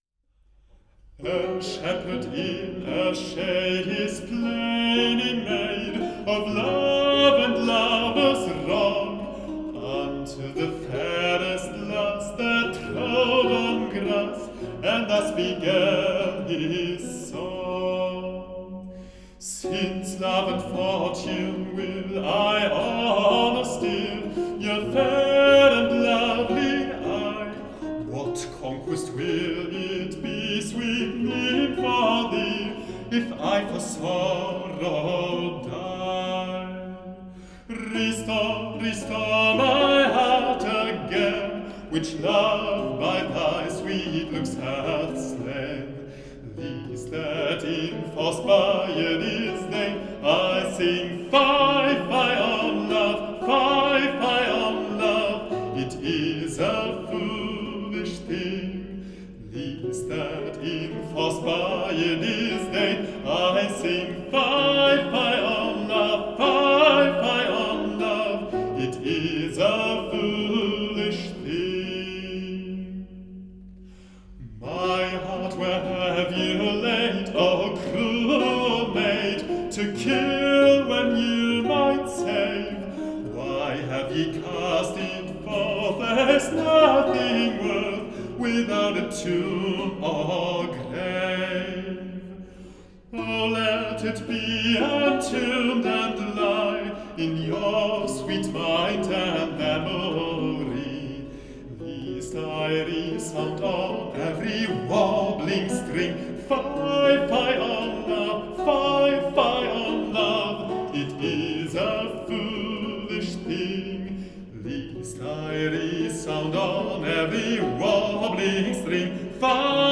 Bariton